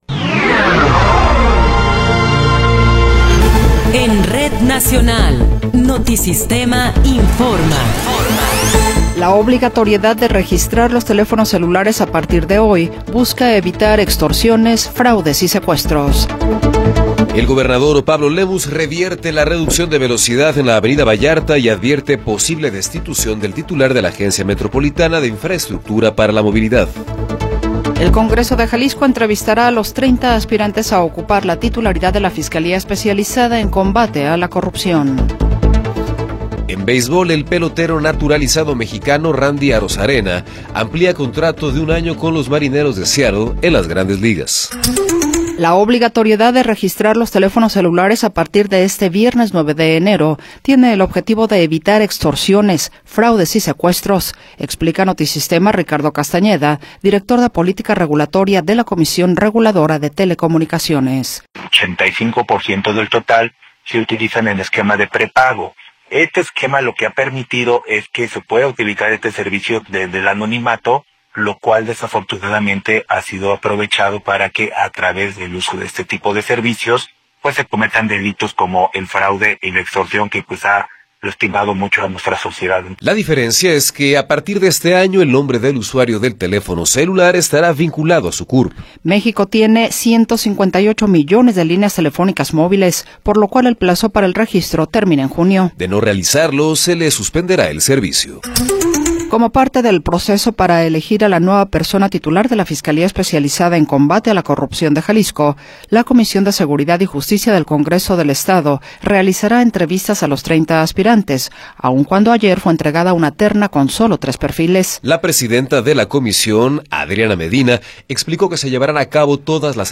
Noticiero 14 hrs. – 9 de Enero de 2026